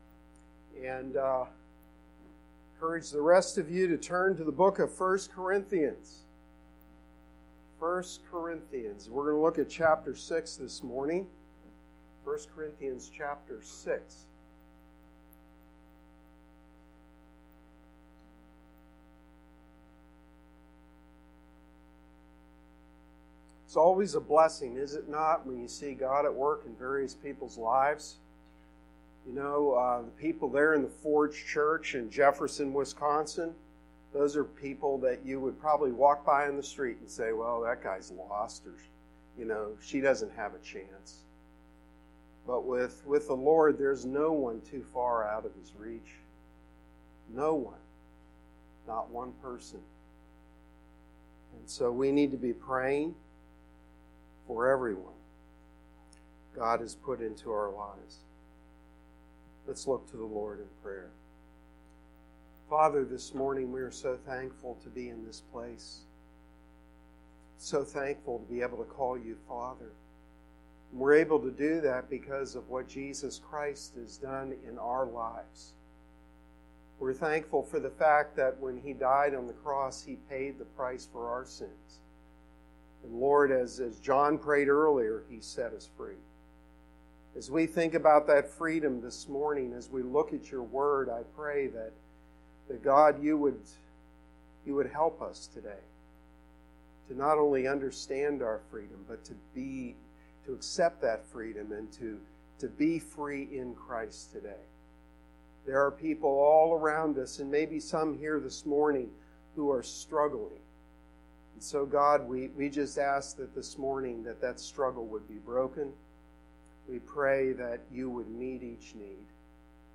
Sermon-7-7-19.mp3